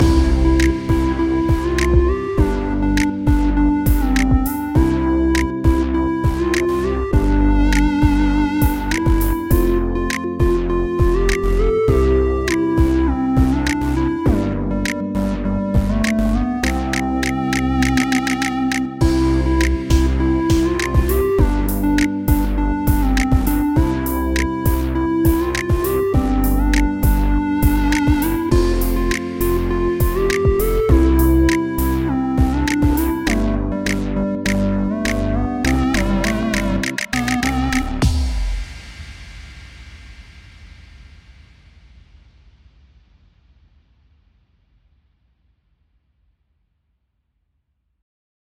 Hey Diddle Diddle (R&B Remix)